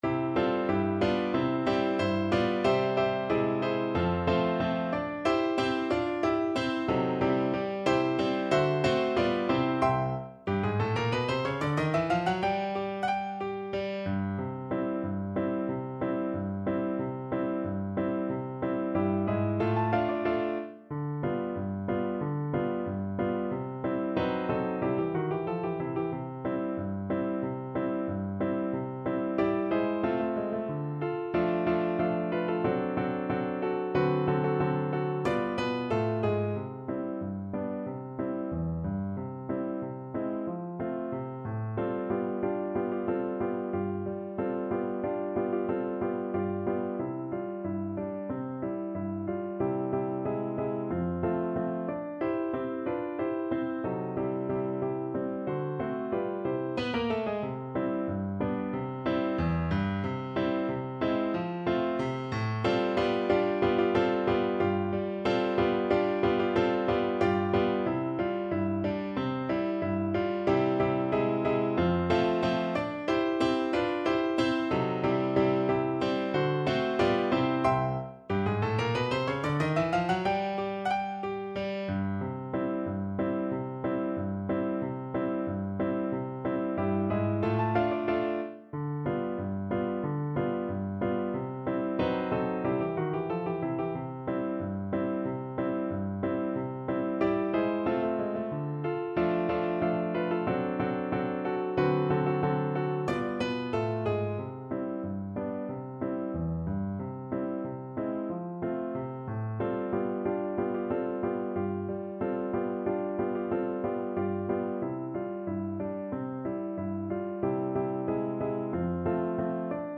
Allegretto =92
2/4 (View more 2/4 Music)